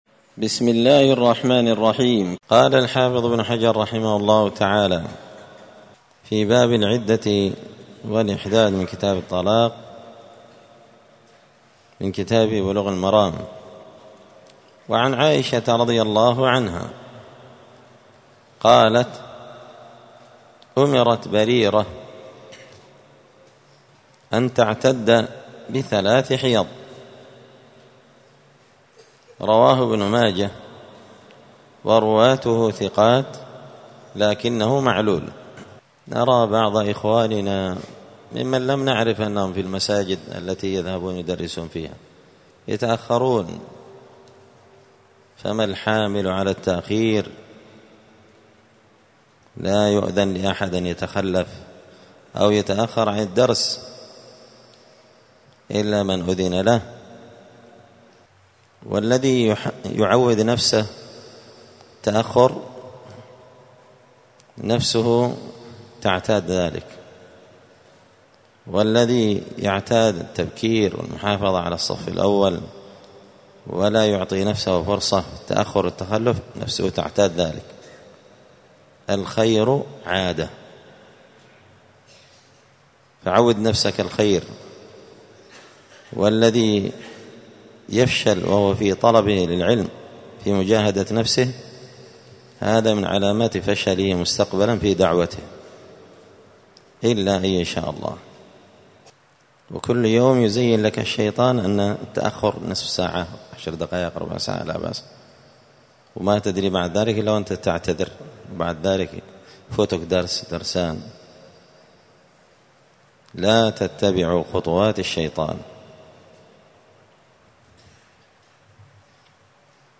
*الدرس التاسع عشر (19) {تابع لباب العدة الإحداد والاستبراء}*